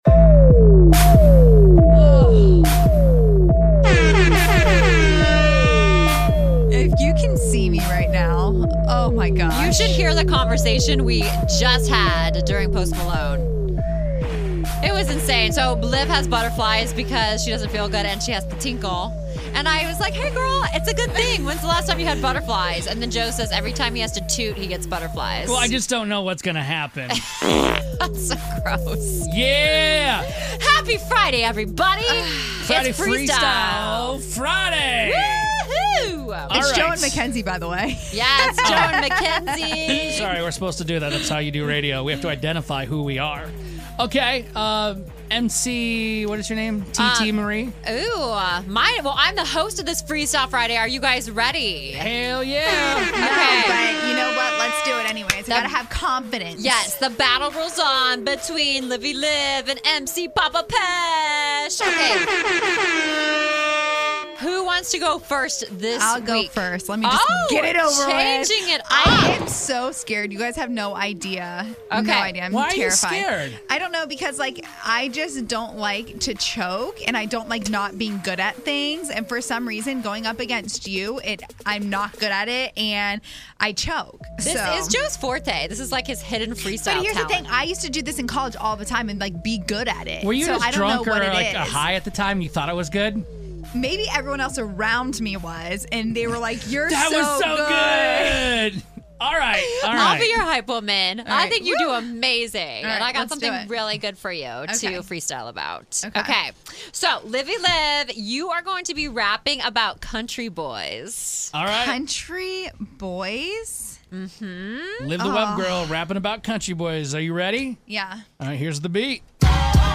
Friday Freestyle Rap Battle